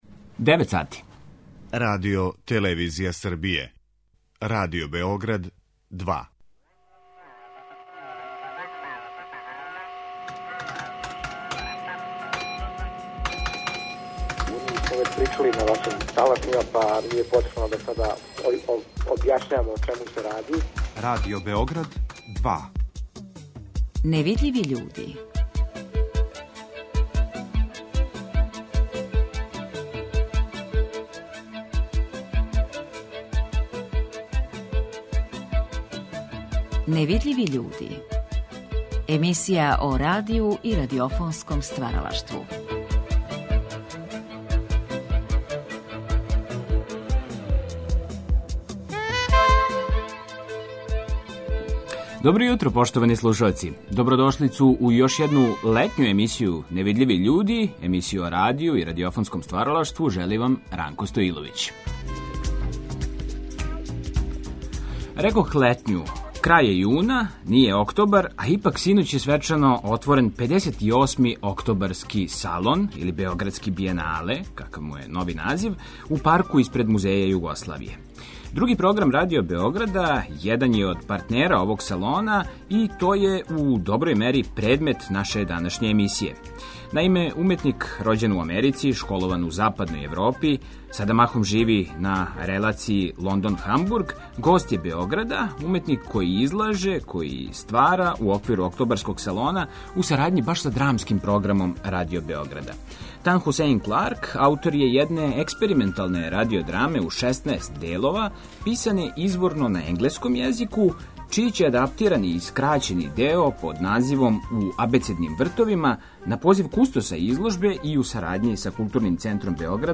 Емисија о радију и радиофонском стваралаштву